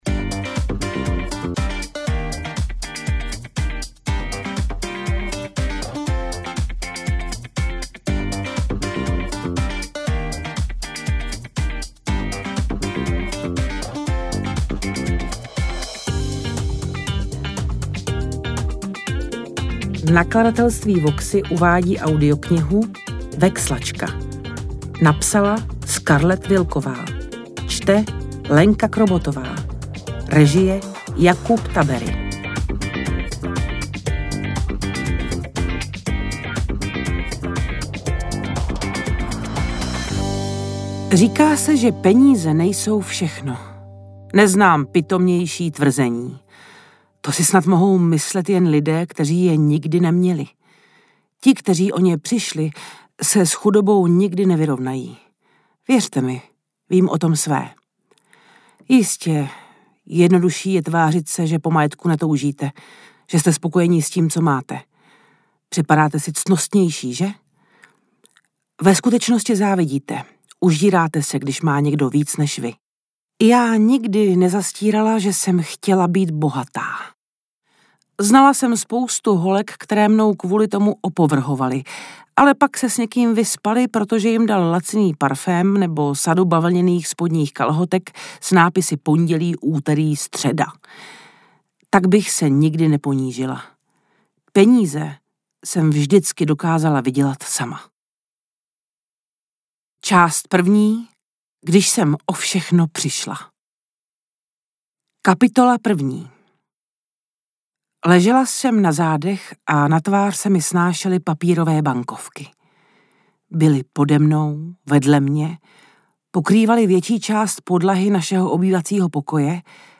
Interpret:  Lenka Krobotová
AudioKniha ke stažení, 51 x mp3, délka 9 hod. 41 min., velikost 528,3 MB, česky